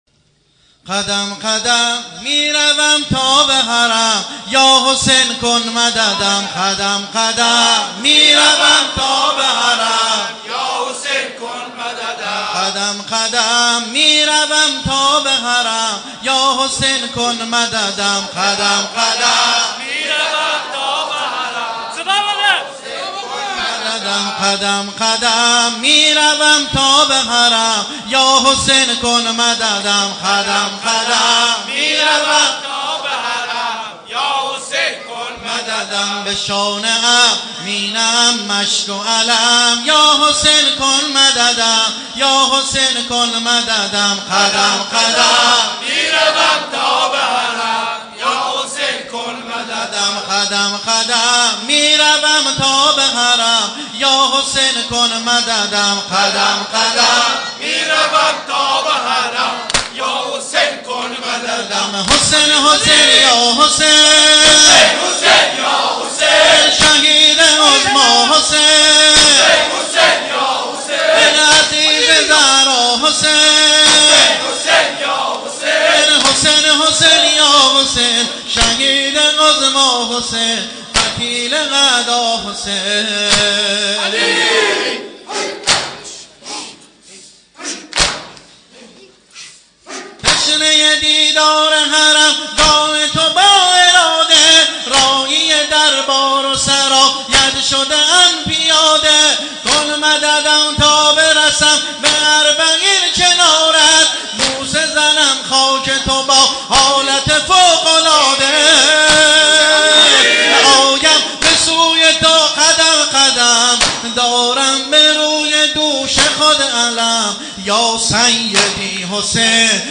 متن و سبک نوحه اربعین -( قدم قدم ، می روم تا به حرم )